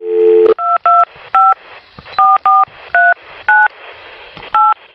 Telefono MARCANDO